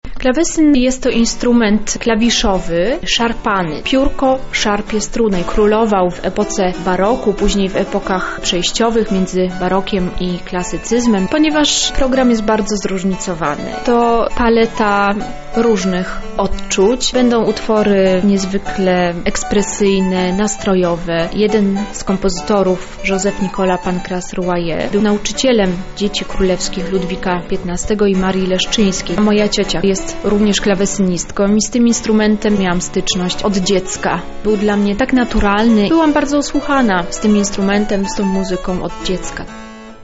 Dzielnicowy Dom Kultury Węglin wypełnił się dźwiękami klawesynu. Choć skojarzenia z tym instrumentem wiążą się główne z barokiem, publiczność usłyszała przekrój muzyki od średniowiecza po czasy współczesne.
Koncert klawesynowy
koncert-klawesyn.mp3